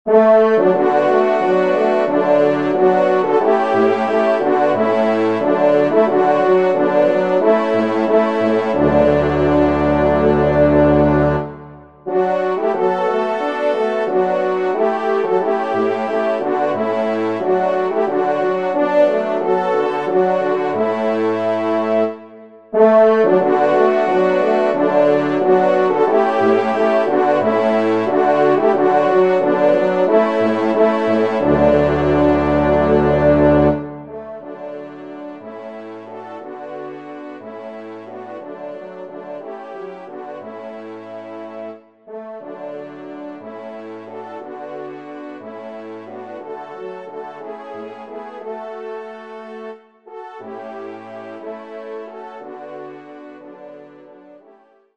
ENSEMBLE